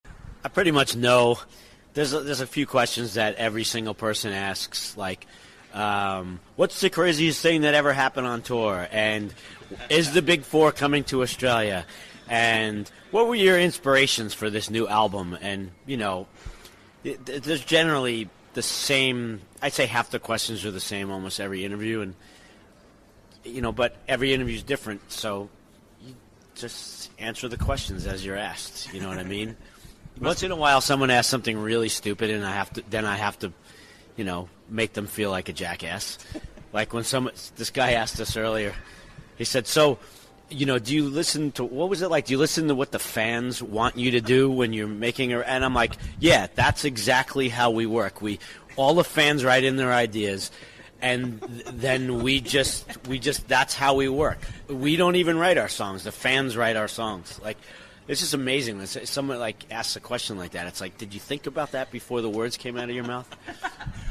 At least that’s what Anthrax’s Scott Ian and Rob Caggiano told us when we met them at Sydney’s Intercontinental Hotel on the afternoon of Tuesday, September 27 2011.
Hear more of this light-hearted 2011 chat – organised to promote the Worship Music album – on demand, by hitting our PATREON PAGE where there is a veritable goldmine of such gems – all for just USD1.50 per month!